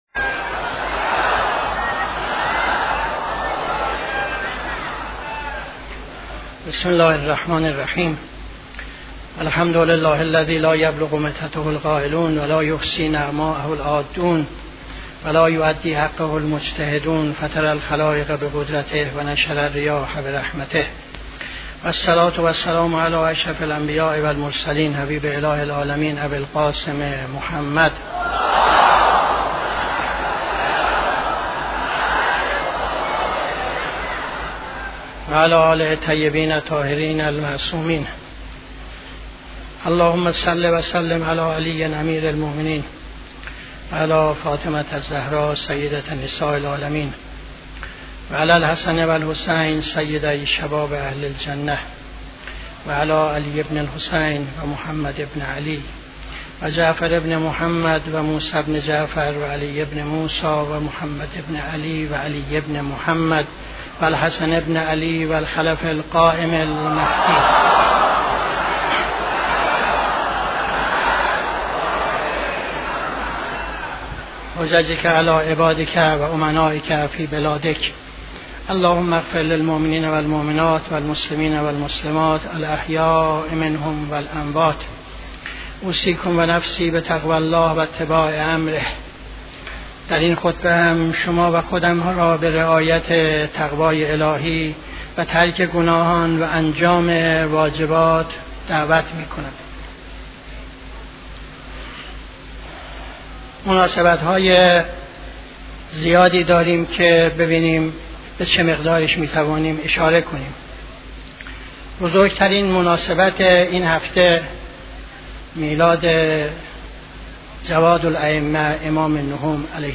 خطبه دوم نماز جمعه 02-09-75